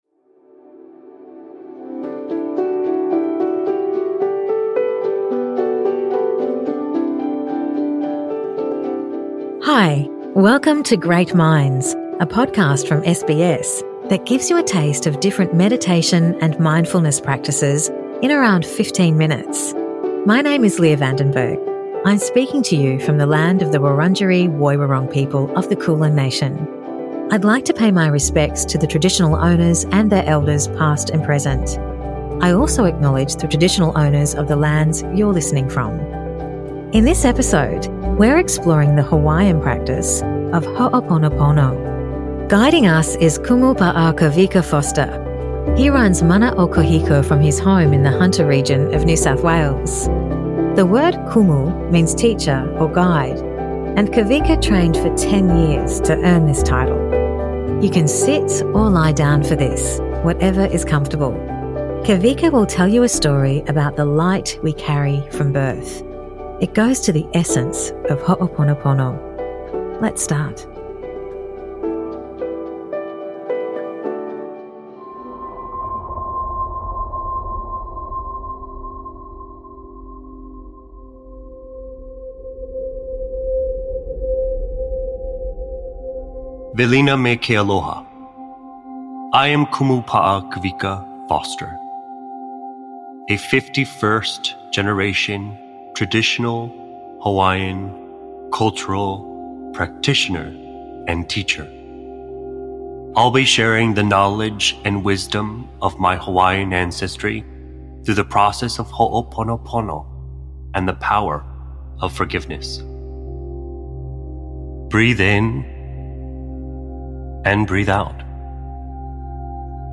Ho’oponopono meditation 2: Your light